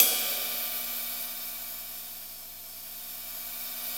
CYM XRIDE 2A.wav